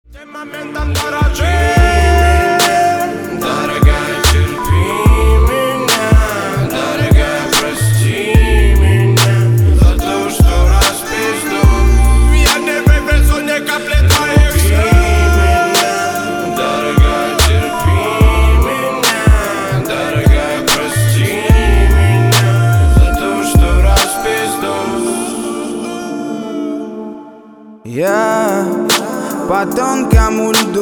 • Качество: 320, Stereo
мужской вокал
мелодичные
dance
спокойные
регги
медленные
качающие